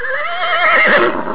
click to hear the pony neighing...
horse04.au